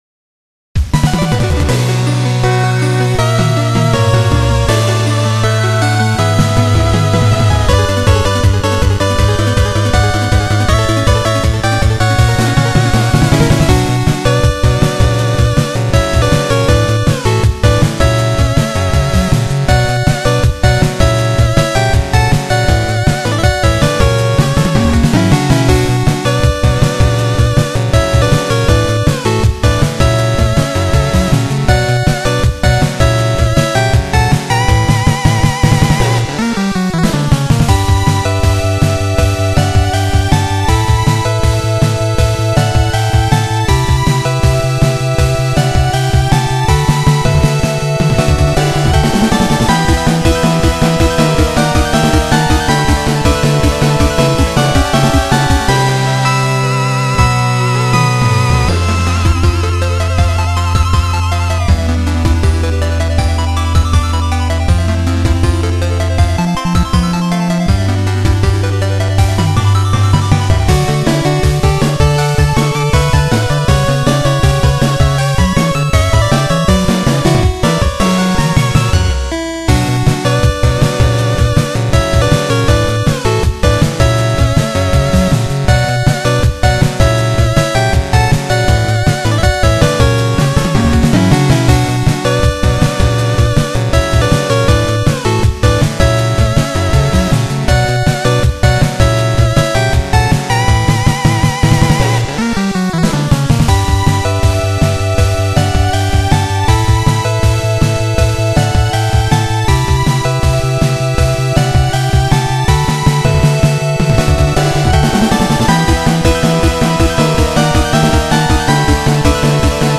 14．決死の進撃 最終面後半BGM。
ラスボス直前の攻防に相応しい楽曲。
イントロに下りのアルペジオを導入しました。